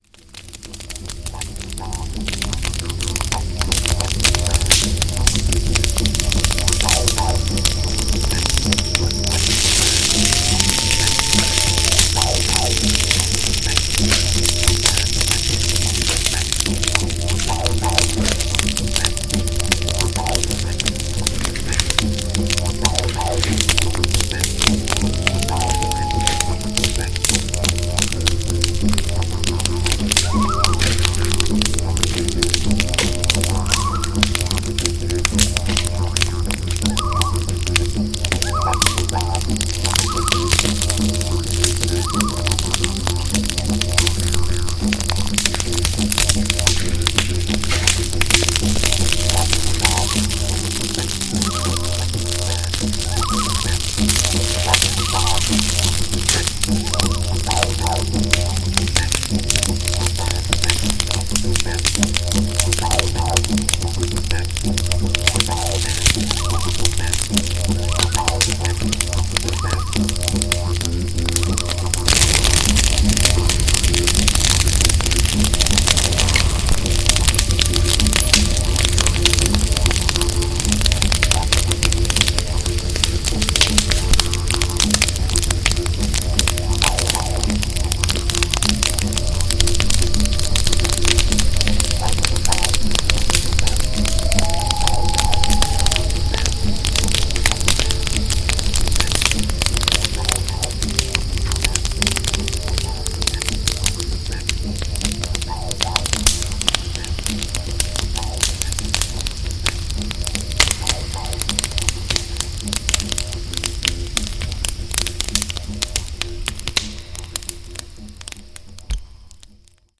Extrait 1 : Ruisseau, Vent, Feu – 1
1_Ruisseau_Vent_Feu_1.mp3